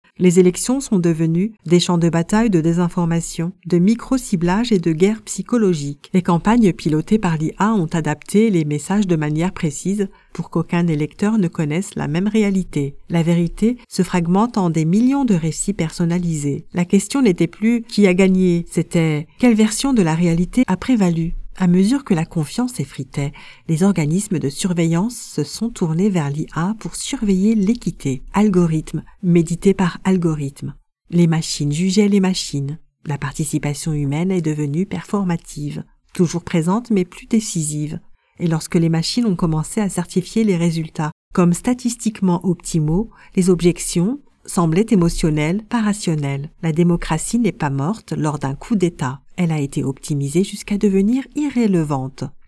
0213demo-audiobook-politic.mp3